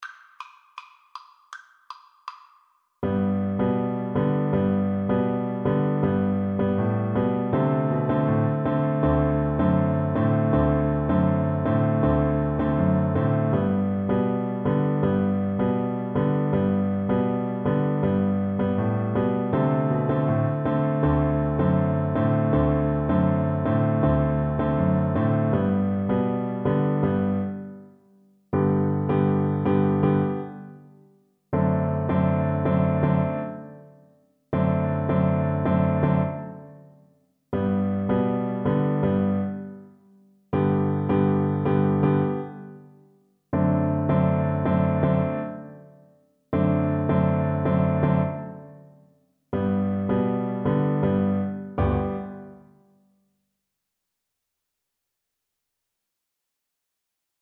Free Sheet music for Soprano (Descant) Recorder
"La Cucaracha" (Spanish: "The Cockroach") is a traditional Spanish folk corrido that became popular in Mexico during the Mexican Revolution.
4/4 (View more 4/4 Music)
G major (Sounding Pitch) (View more G major Music for Recorder )
Presto (View more music marked Presto)
World (View more World Recorder Music)